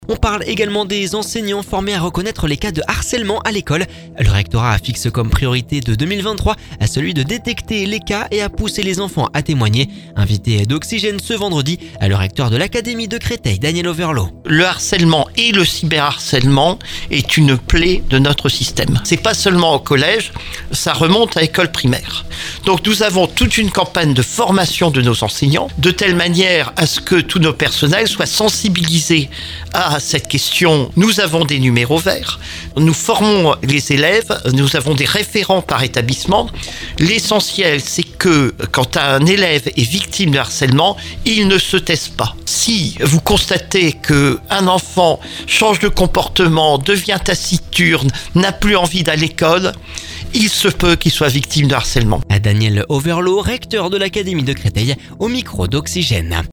Le rectorat fixe comme priorité 2023 de détecter les cas, et à pousser les enfants à témoigner. Invité d'Oxygène ce vendredi, le recteur de l'Académie de Créteil Daniel Auverlot.